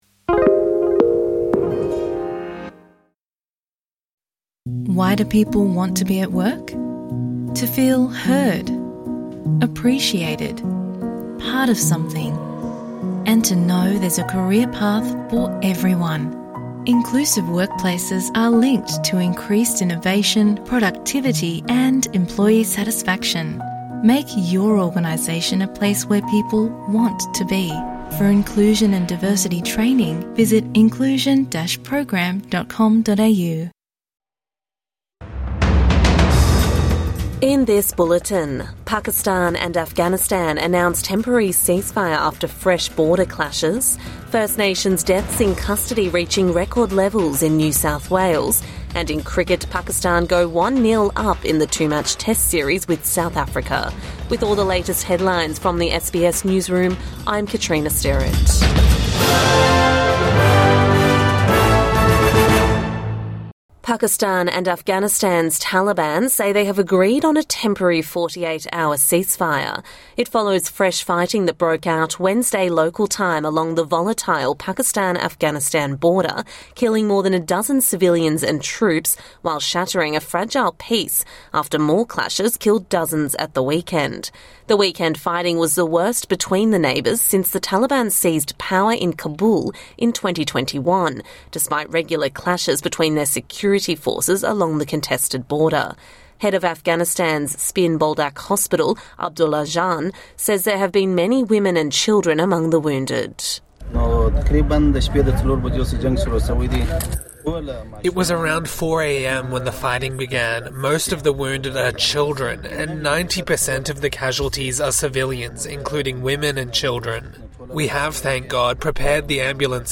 Pakistan and Afghanistan agree on 48-hour temporary ceasefire | Morning News Bulletin 16 October 2025